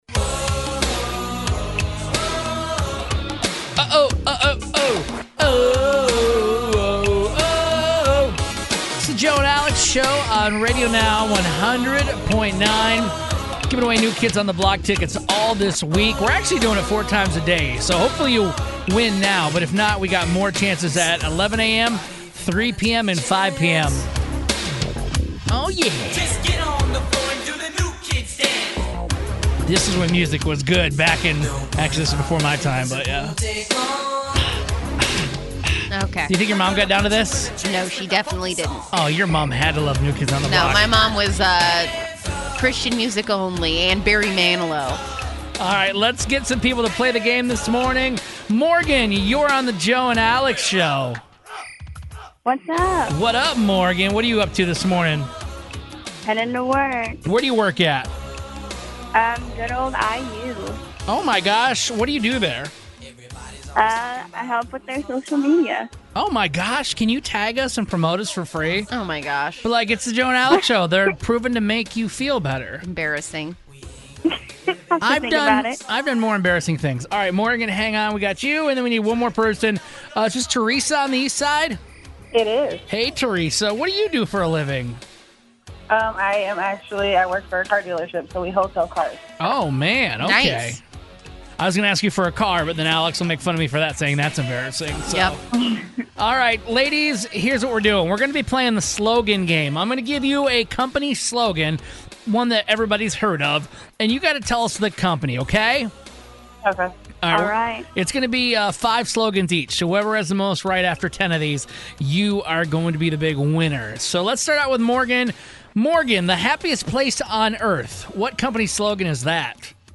We put two listeners head to head to see which one could get the most popular brand slogans correct in order to win tickets to go see New Kids On The Block.